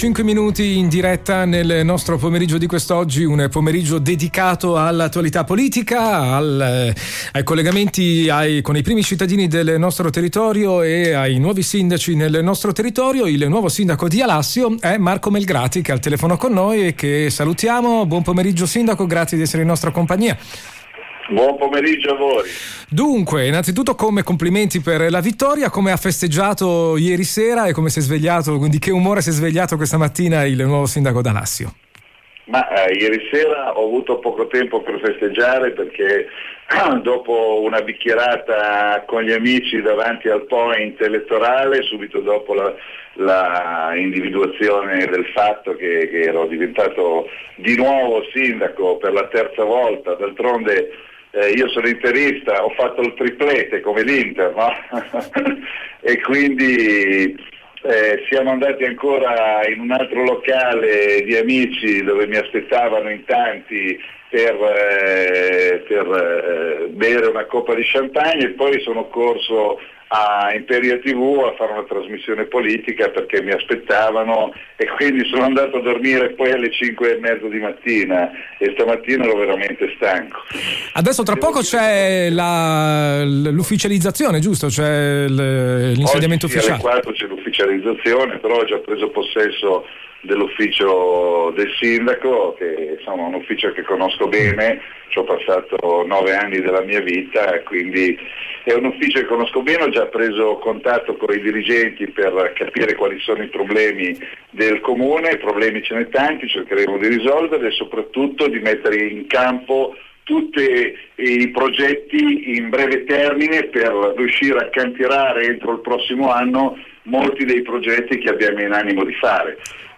Intervista audio al nuovo sindaco di Alassio Marco Melgrati (4.7 MB)
Intervista_audio_al_nuovo_sindaco_di_Alassio_Marco_Melgrati.mp3